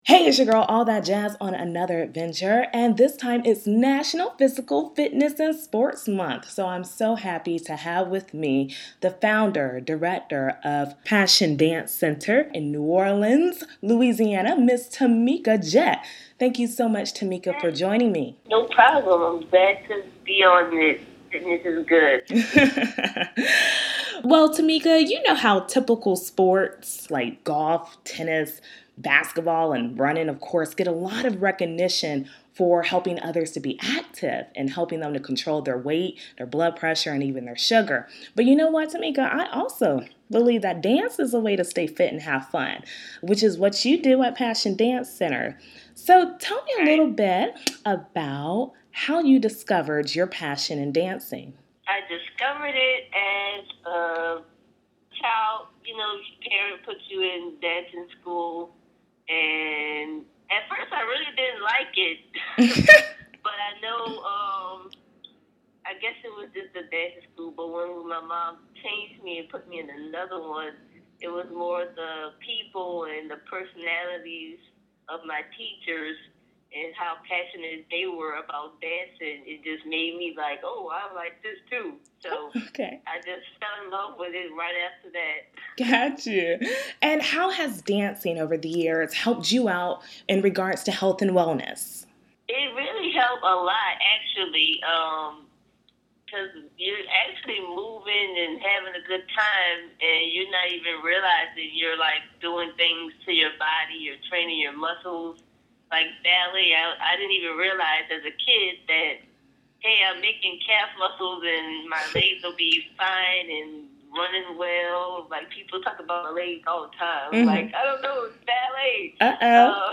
A One on One Conversation